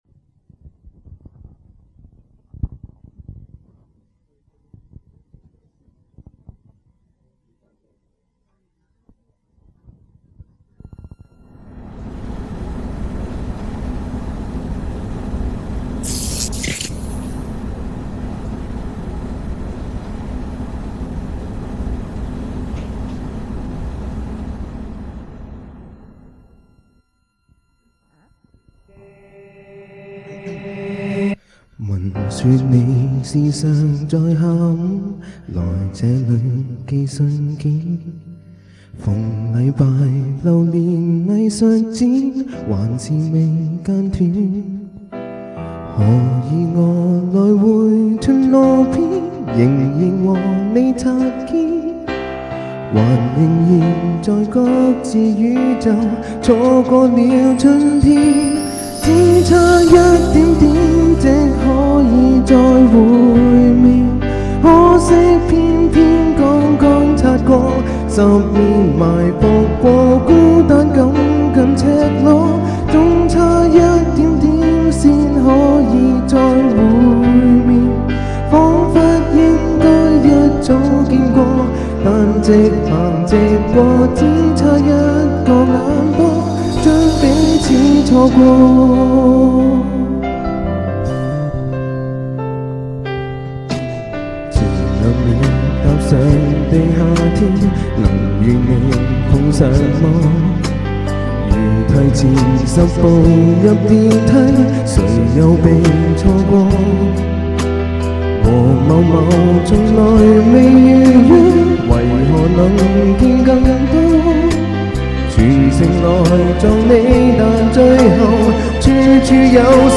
On December 4th 2004, members of various classes gathered at Emerald City Chinese Restaurant to find out who is the King/Queen of Karaoke.